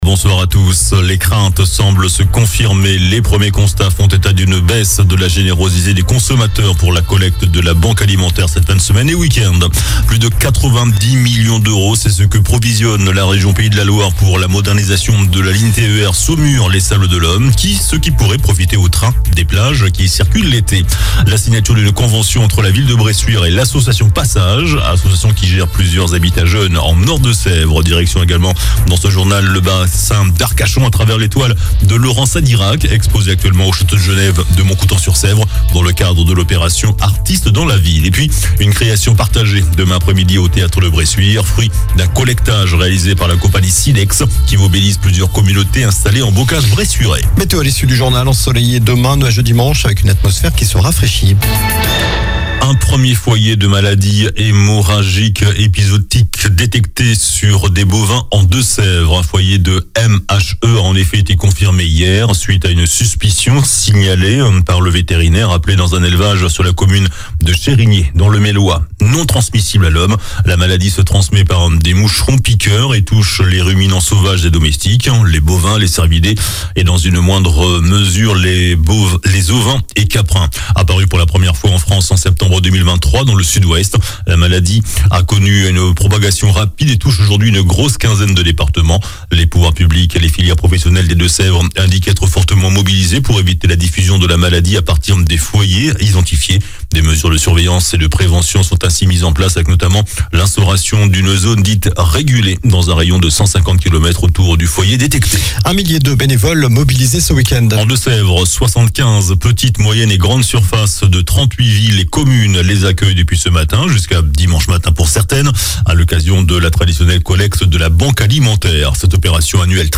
JOURNAL DU VENDREDI 24 NOVEMBRE ( SOIR )